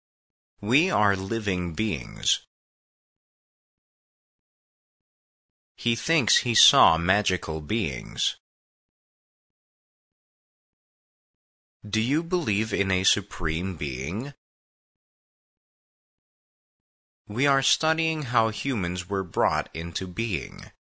being-pause.mp3